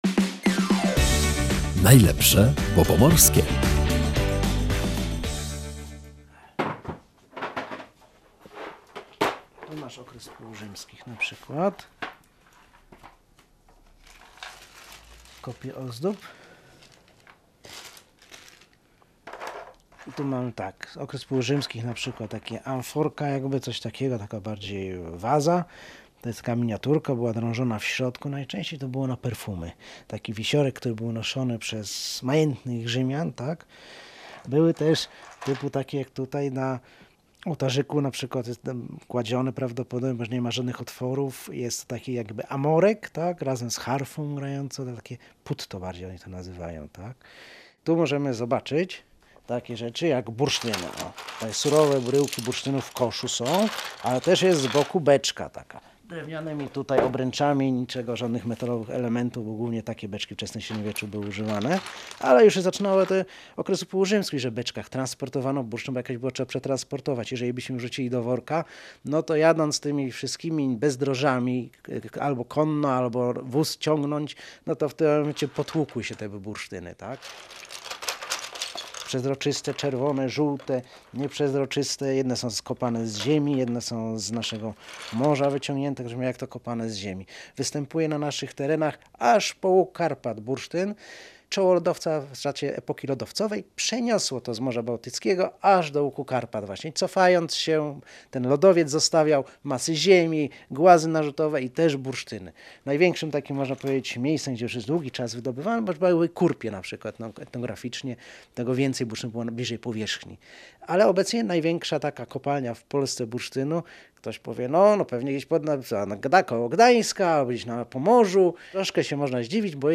W tym warsztacie historia spotyka się ze współczesnością i rozmawiają o… bursztynie